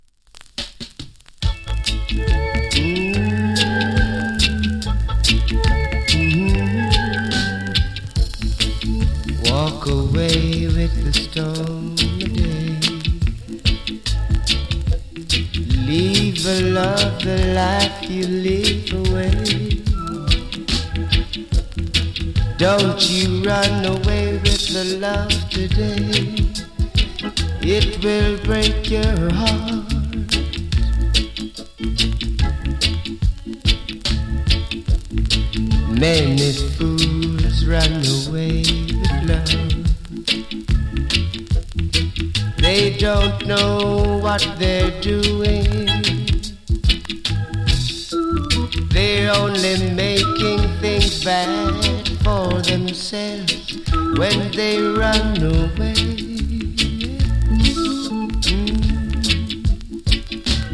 ＊スリキズ有り。チリ、パチノイズ有り。
甘い VOCAL の NICE EARLY REGGAE !!